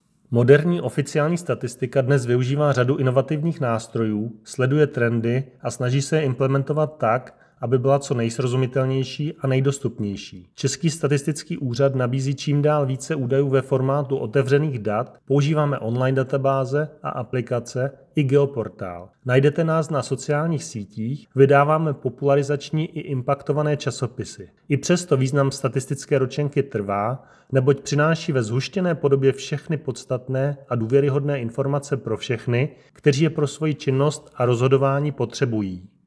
Vyjádření Marka Rojíčka, předsedy Českého statistického úřadu, soubor ve formátu MP3, 1.38 MB